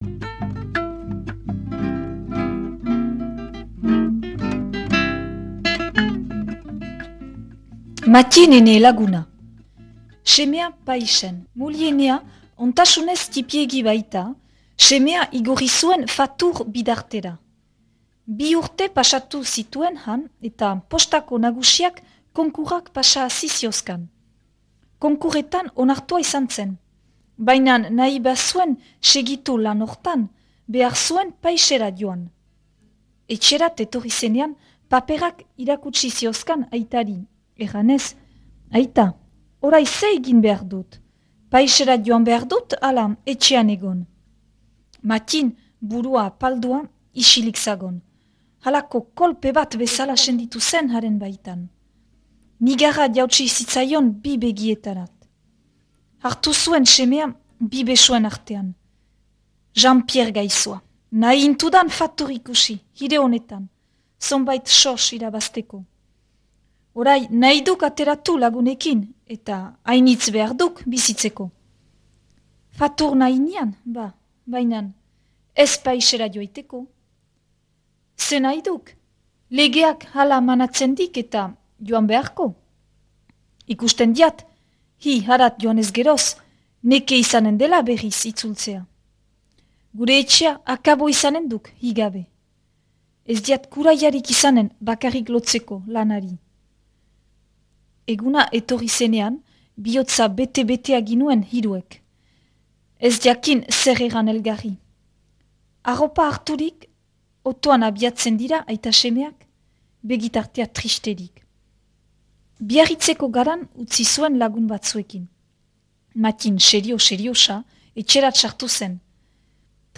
Gure artxiboetarik atera sail hau, Ahetzeko Mattin Trecu (1916-1981) bertsolariari eskainia.
irakurketa